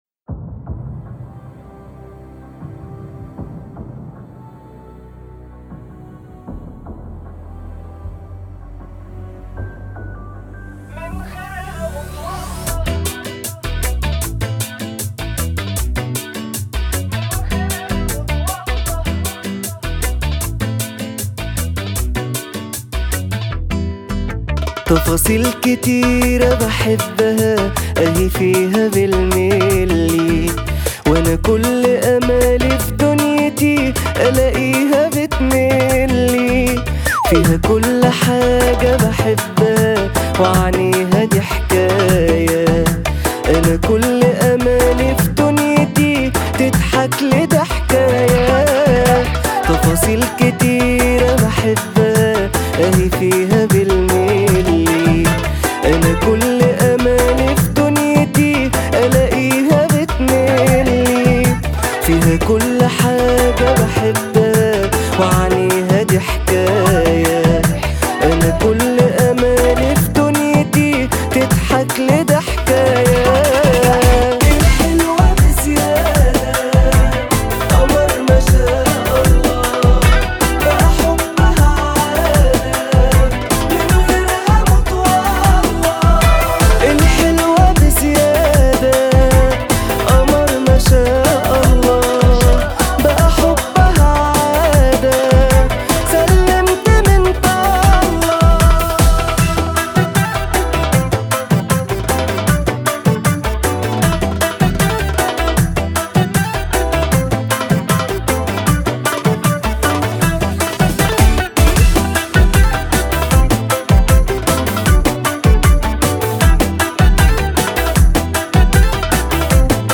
اغاني مصرية